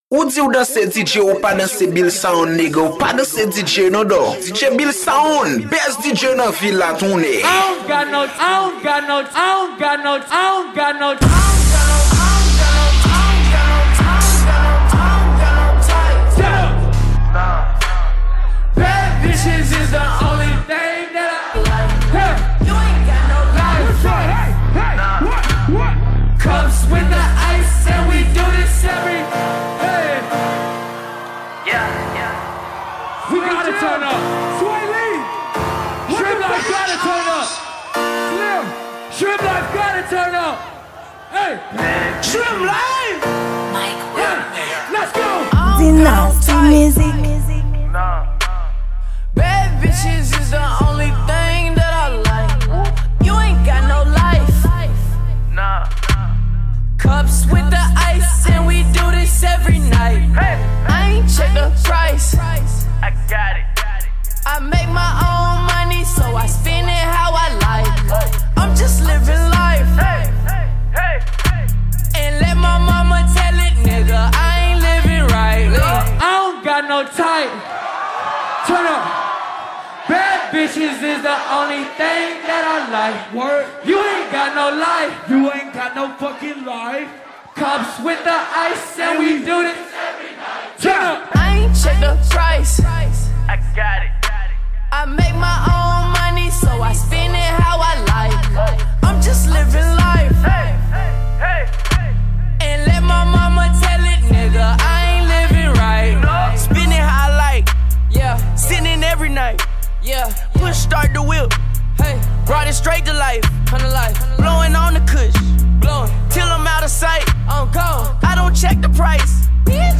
Genre: Remix.